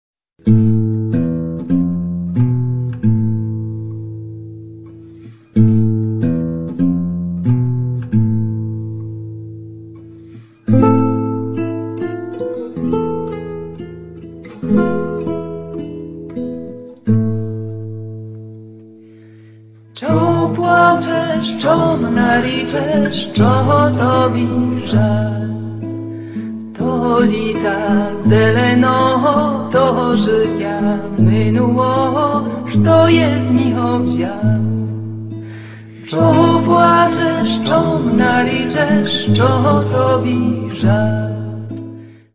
nagranie z łemkowskiego wesela z 1928 r.